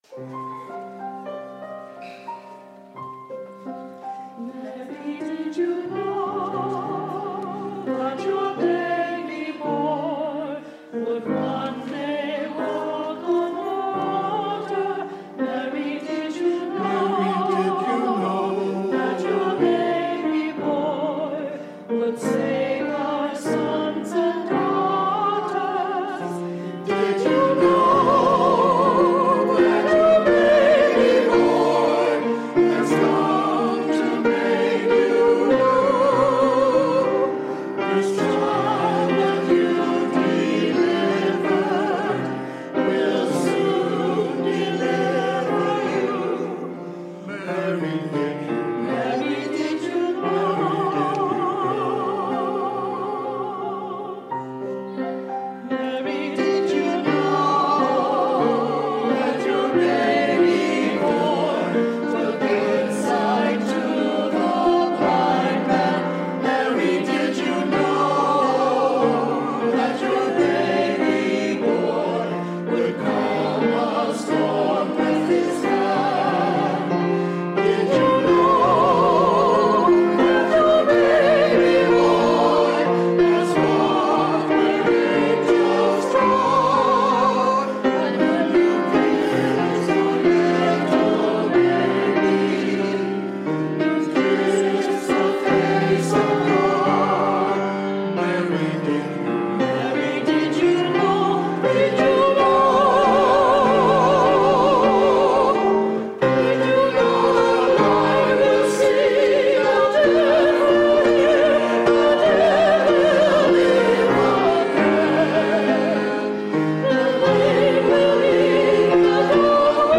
Mary Did You Know (Chior)